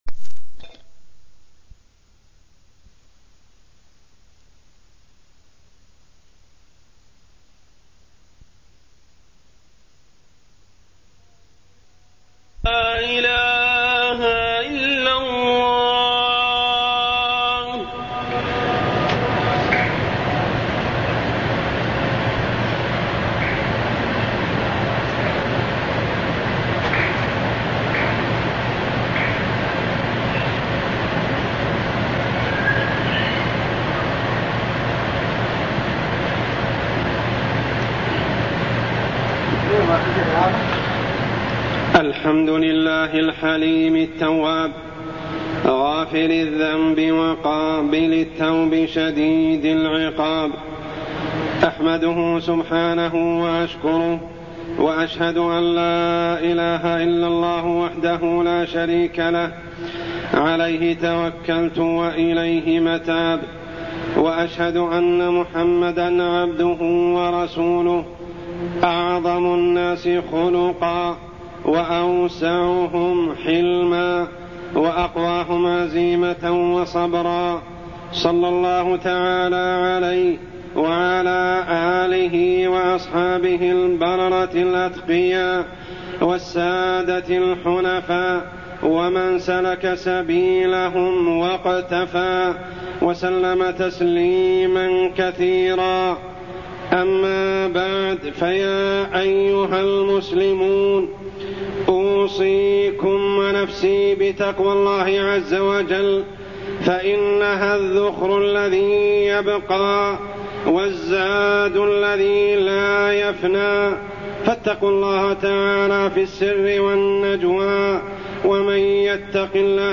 تاريخ النشر ٢٦ ربيع الثاني ١٤٢١ هـ المكان: المسجد الحرام الشيخ: عمر السبيل عمر السبيل الإتصاف بالحلم